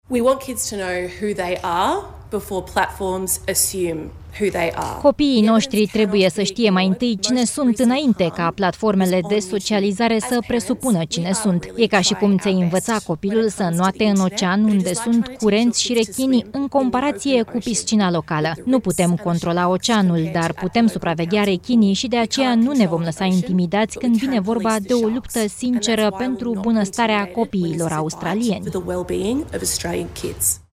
Ministrul Comunicațiilor, Annika Wells: „E ca și cum ți-ai învăța copilul să înoate în ocean, unde sunt curenți și rechini”